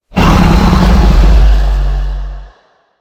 Minecraft Version Minecraft Version snapshot Latest Release | Latest Snapshot snapshot / assets / minecraft / sounds / mob / enderdragon / growl2.ogg Compare With Compare With Latest Release | Latest Snapshot
growl2.ogg